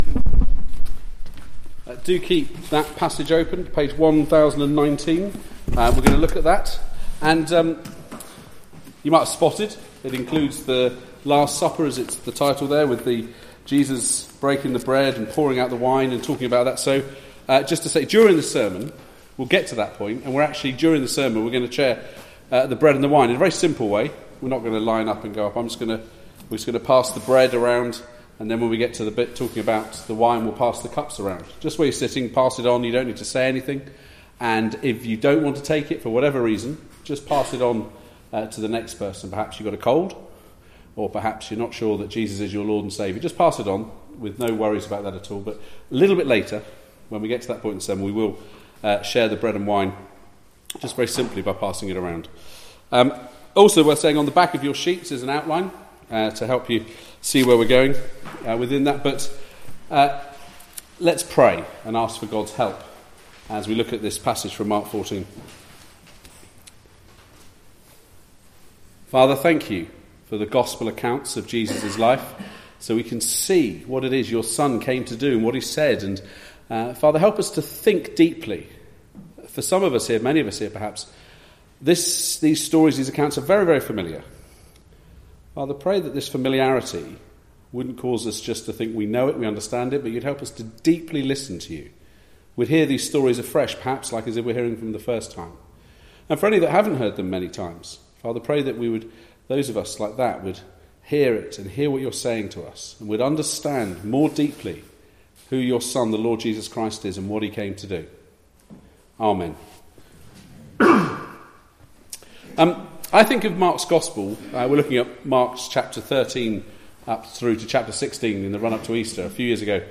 Mark Passage: Mark 13: 1-27 Service Type: Weekly Service at 4pm Bible Text